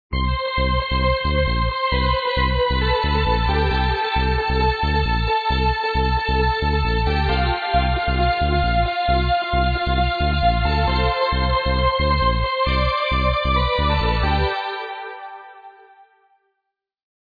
ID this trance song please